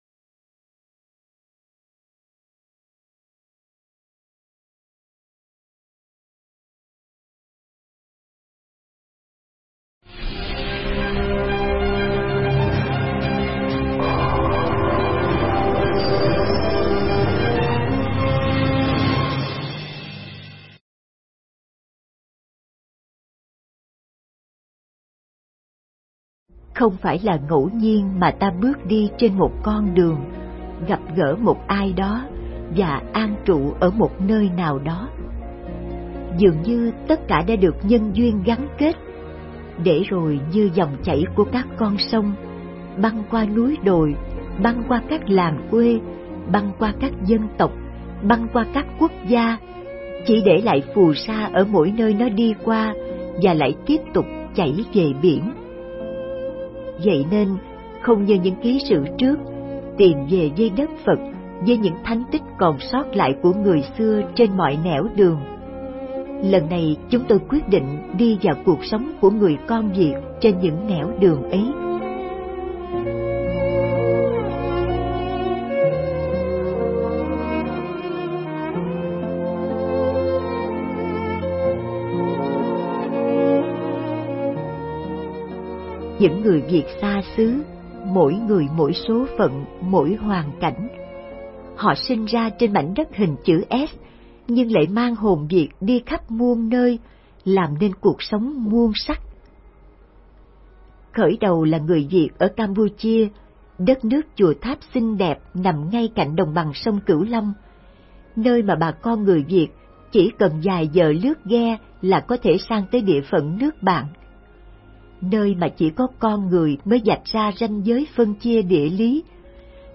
Nghe Mp3 thuyết pháp Biết Bến Nào Trong